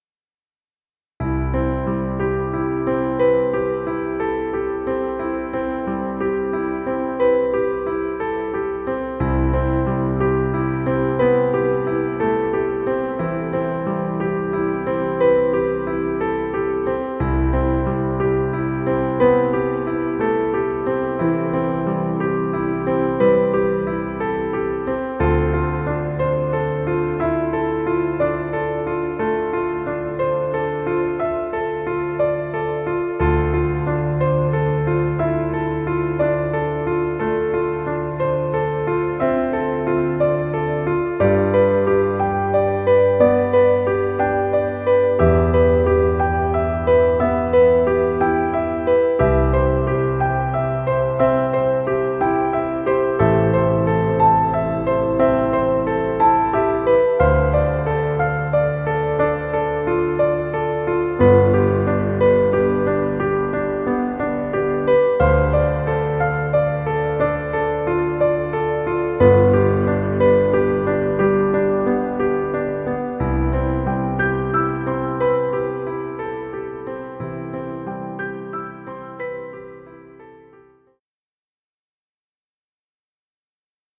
Piano romantic music.